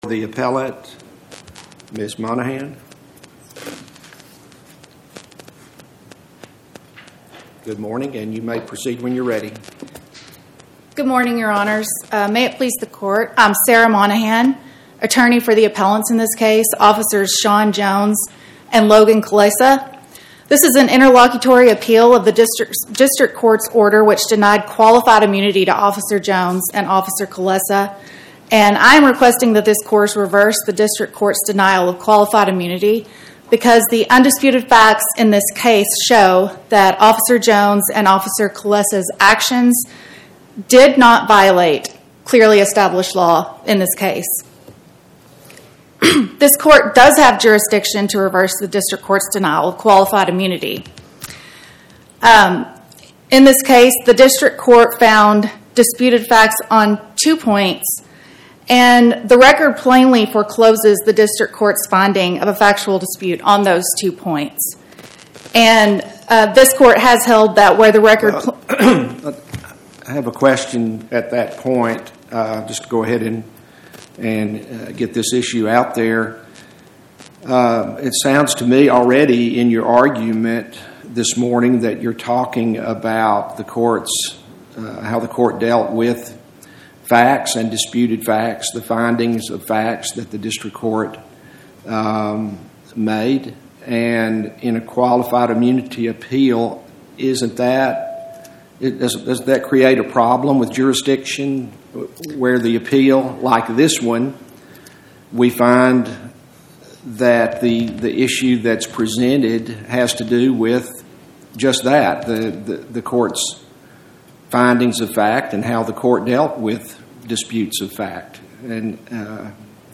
Oral argument argued before the Eighth Circuit U.S. Court of Appeals on or about 01/14/2026